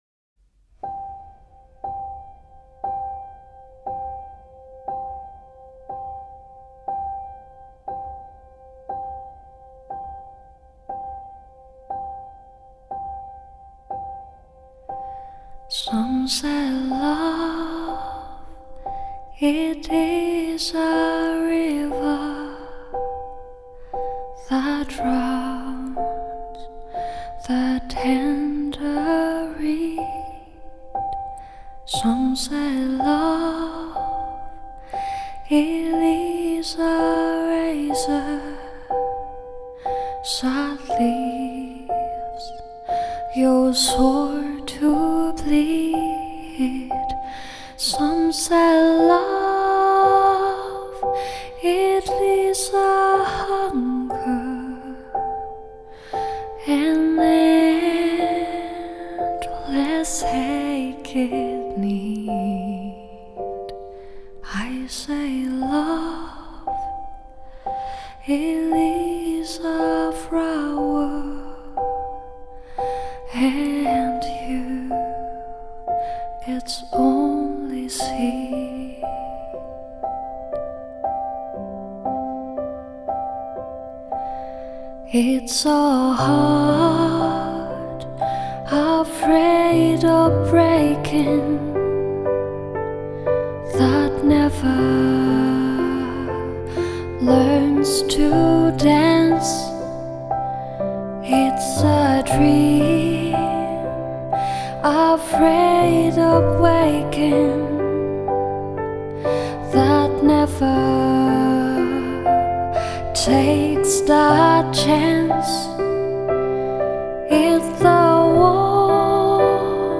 Aoi用她纯净透明的声音将经典重新演绎，静下心来品曲，你将获得前所未有的平静。